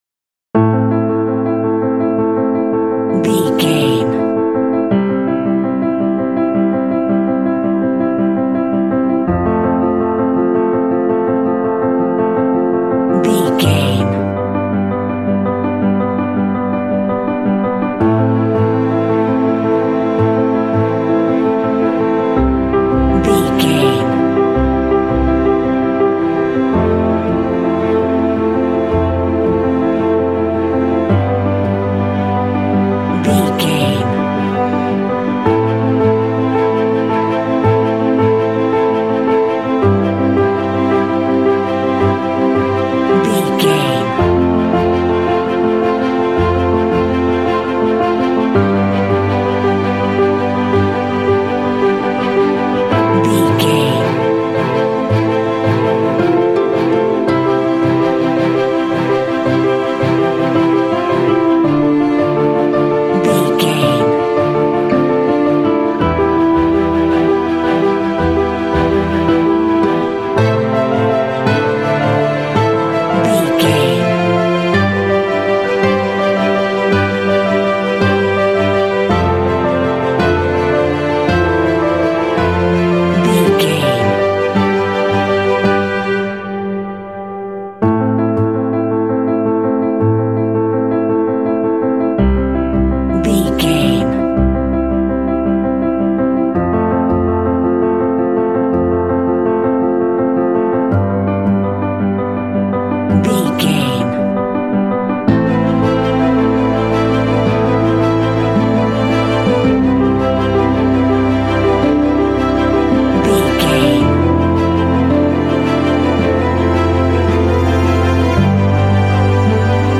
Aeolian/Minor
suspense
foreboding
contemplative
dramatic
piano
strings
cinematic